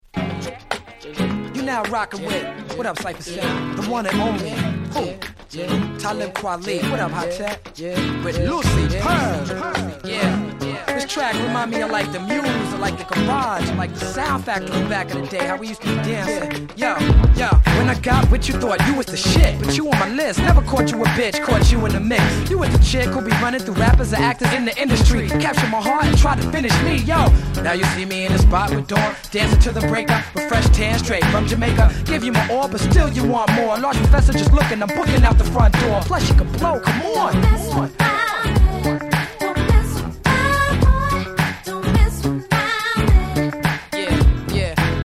00's R&B Classic !!
カッティングギターの気持ち良い名曲中の名曲！